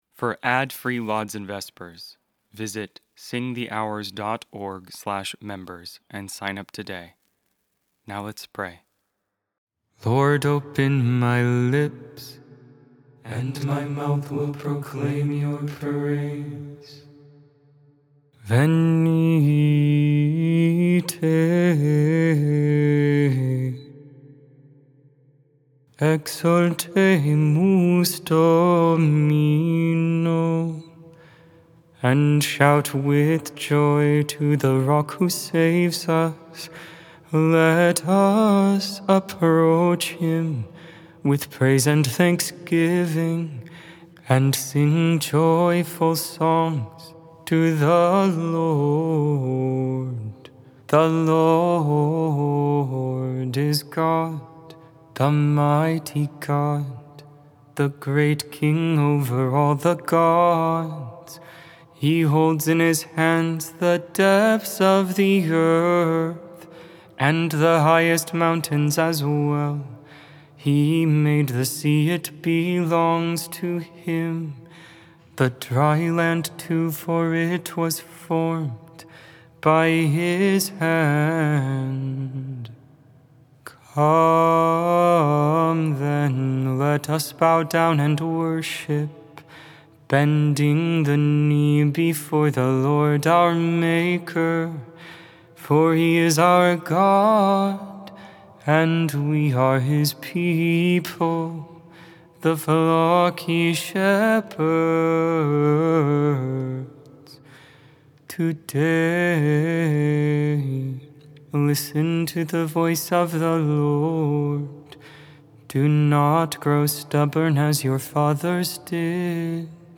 Join for morning (Lauds) and evening (Vespers) prayer. All Hymns, Psalms, and Prayer sung.